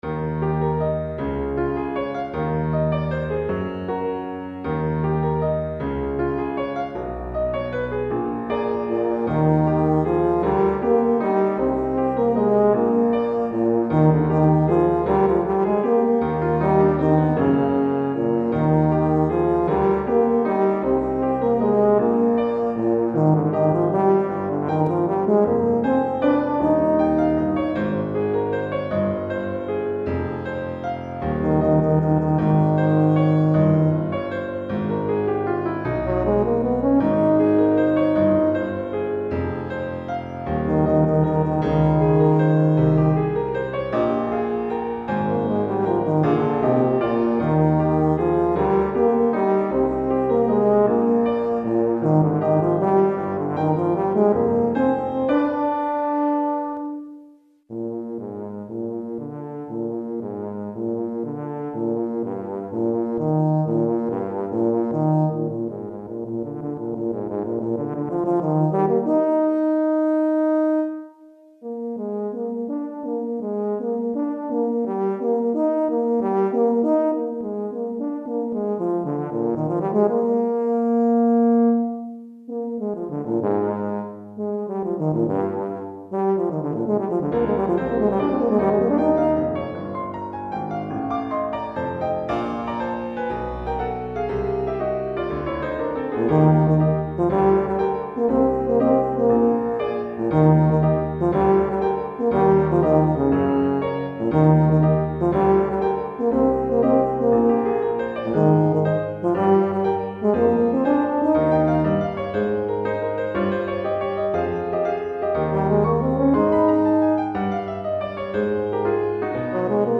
Tuba et Piano